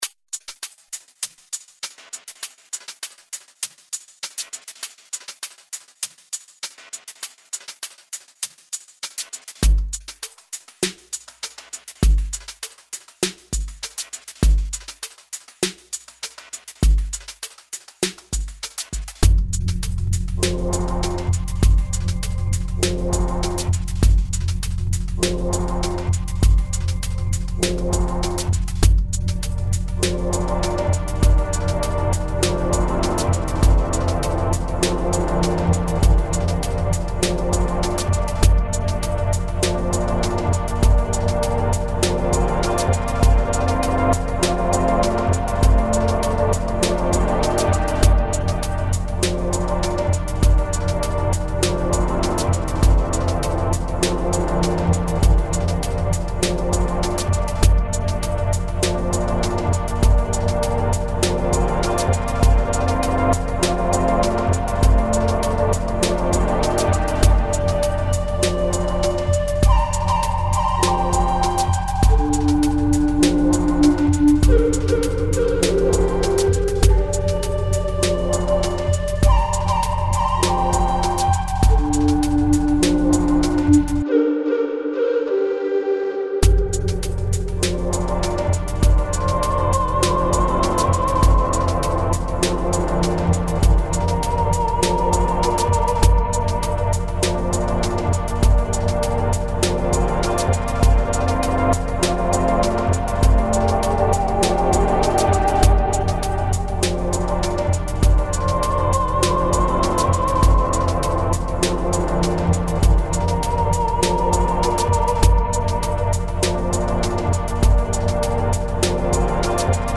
Genre Ambient